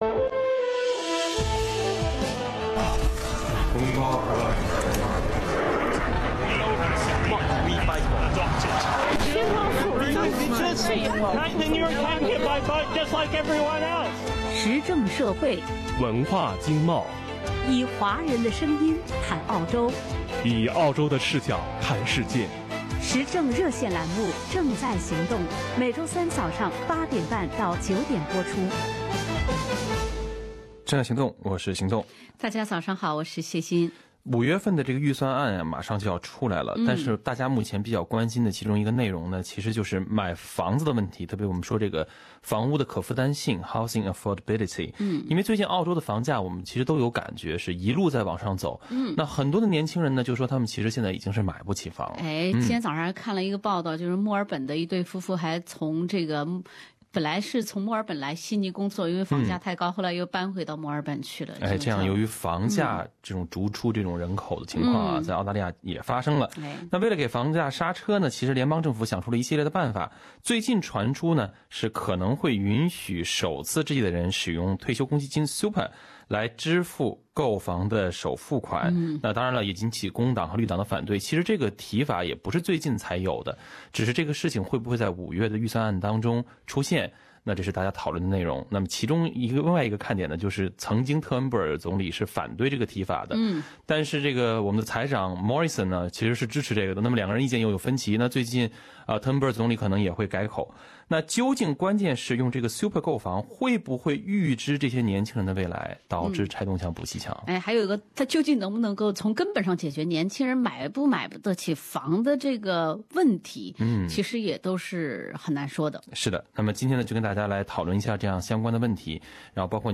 本节目为政策开放性讨论，请听众合理投资资产。